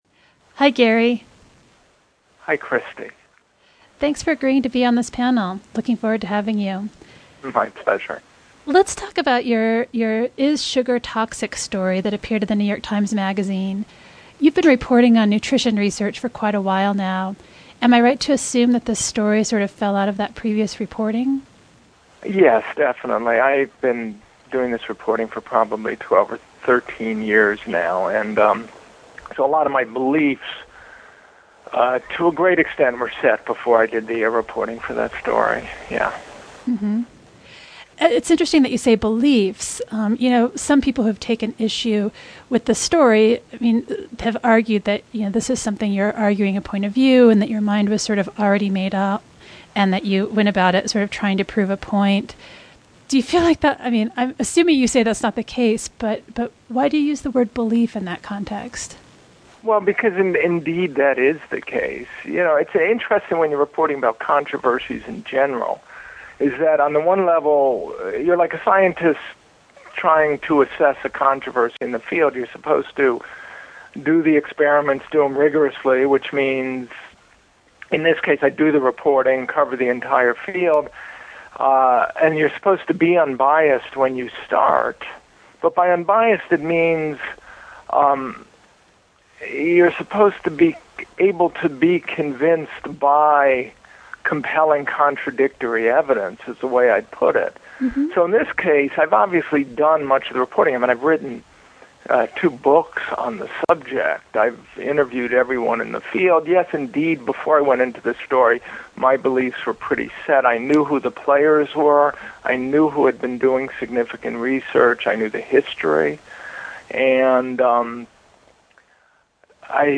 Interview: Gary Taubes